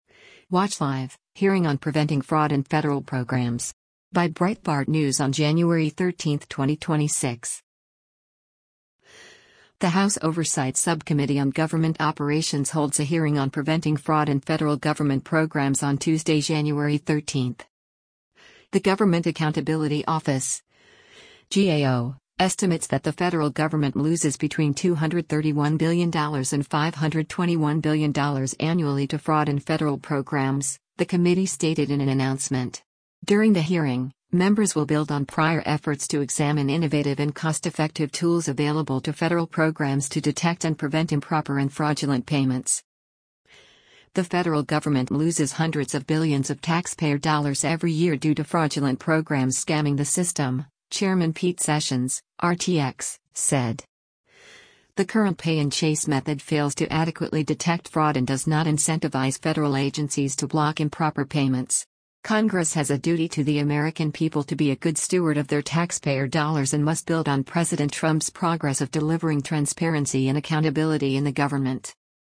The House Oversight Subcommittee on Government Operations holds a hearing on preventing fraud in federal government programs on Tuesday, January 13.